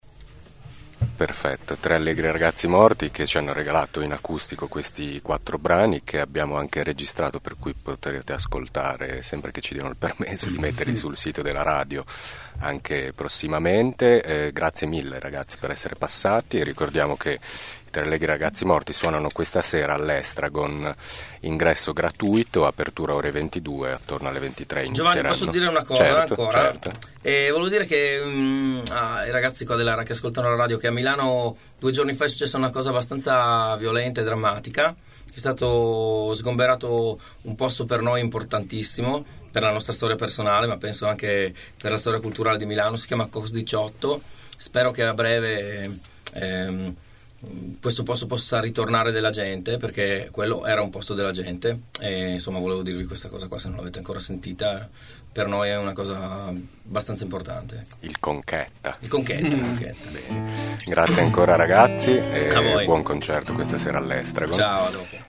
In occasione del loro concerto all’Estragon sabato scorso, sono venuti a trovarci a Thermos i Tre Allegri Ragazzi Morti.
tre-allegri-ragazzi-morti-intervista-4.mp3